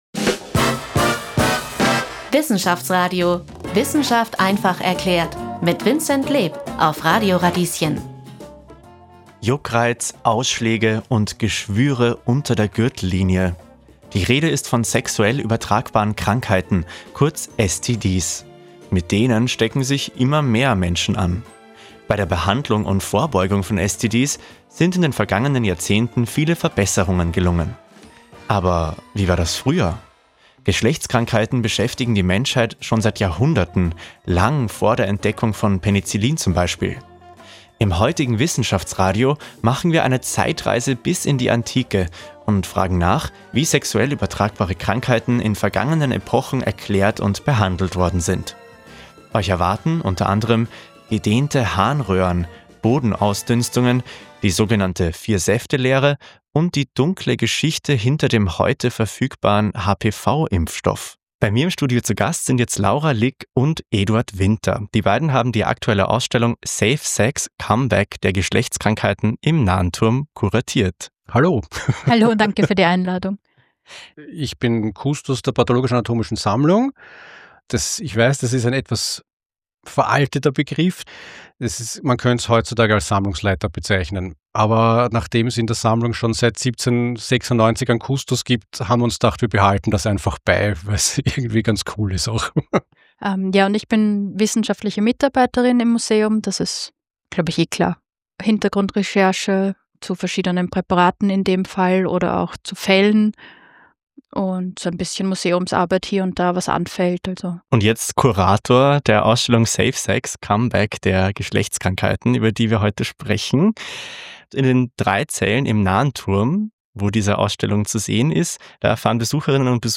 Dieser Podcast ist ein Ausschnitt aus der Radio Radieschen-Sendung vom 18. November 2025.